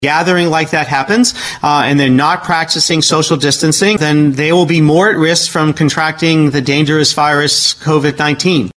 Secretary of Health, Dr. Rachel Levine commented on the protesters.